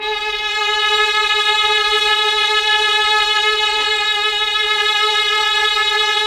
Index of /90_sSampleCDs/Roland LCDP09 Keys of the 60s and 70s 1/KEY_Chamberlin/STR_Cham Slo Str